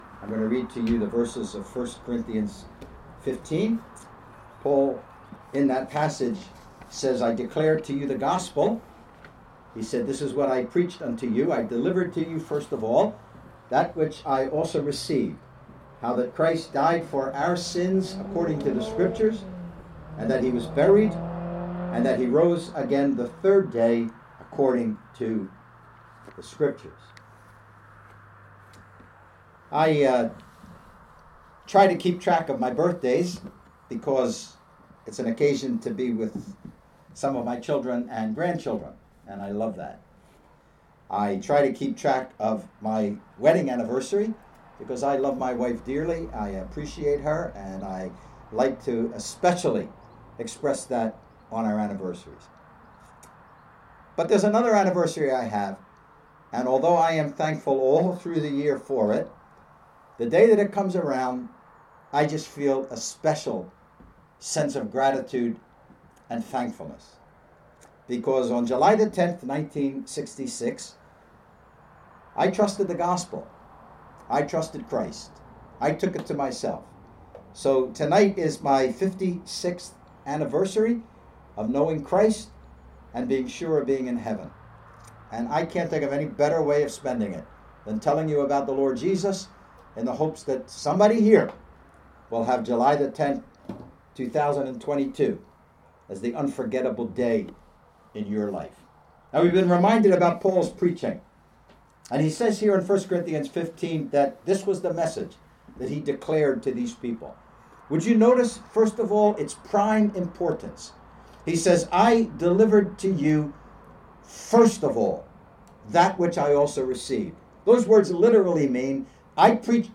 opens up the drive-in gospel meetings by simply proclaiming the truth that “Christ died for our sins”.